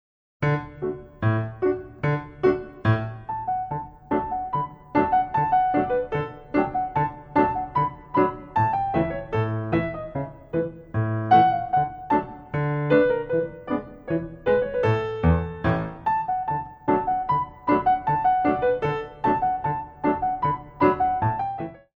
Jump
Bluebird Style